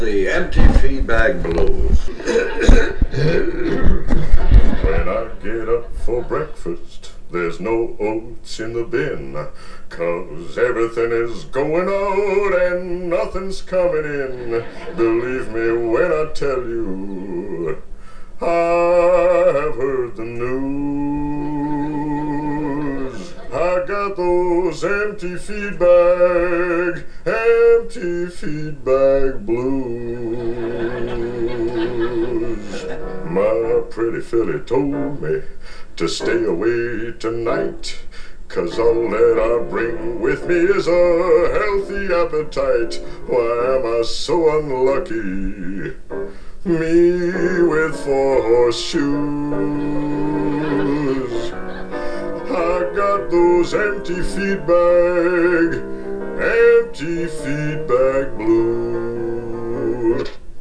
heartfelt number